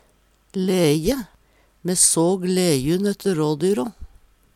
leje - Numedalsmål (en-US)